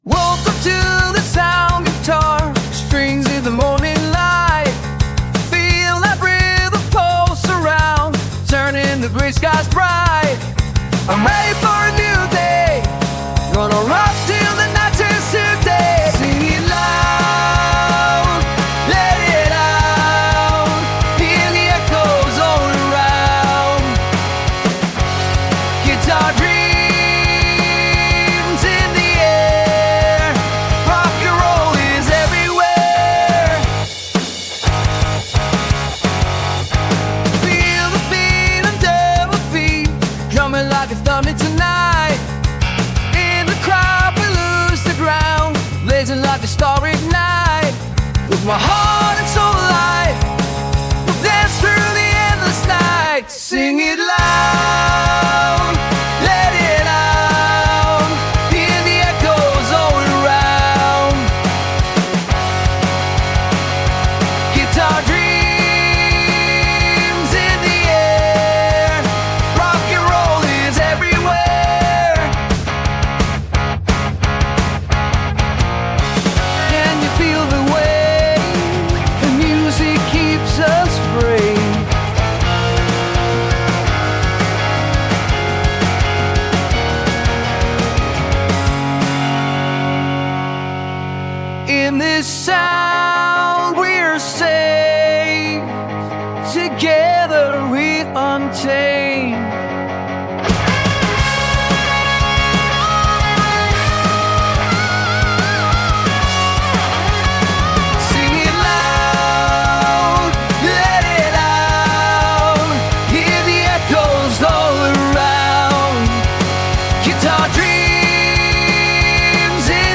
Some rock music, random text, model udio-130